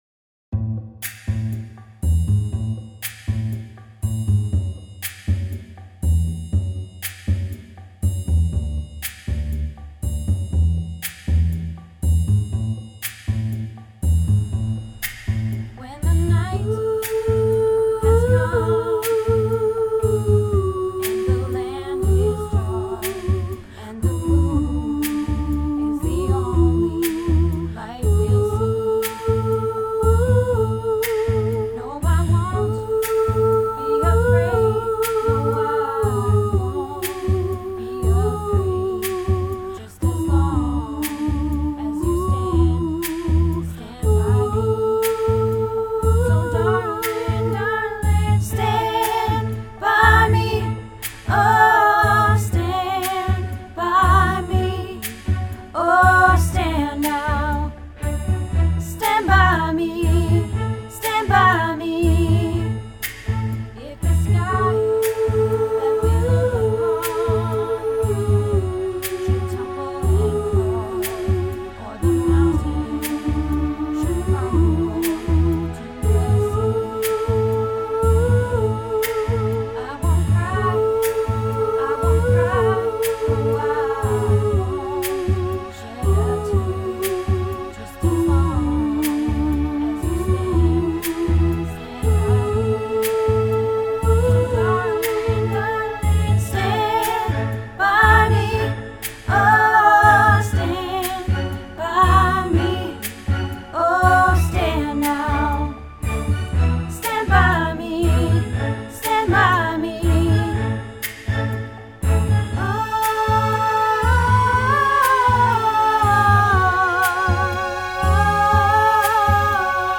Stand By Me - Soprano